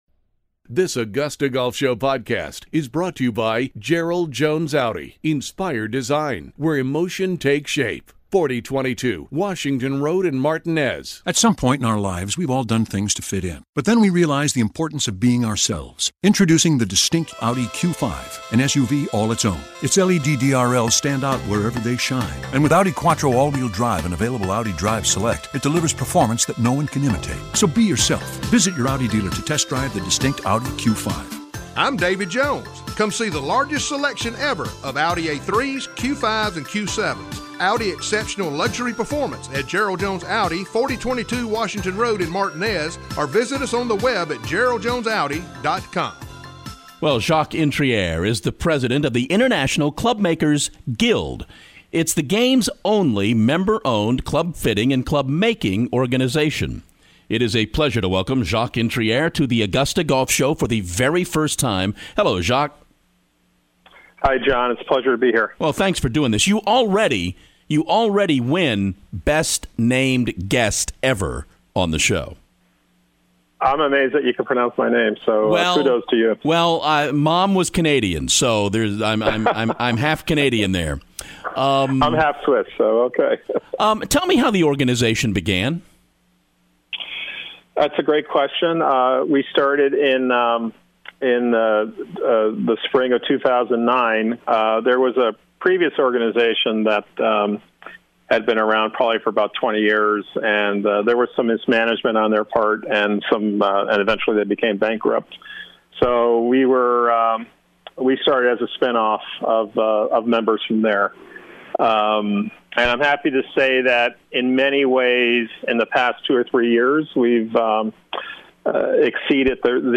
The Augusta Golf Show Interview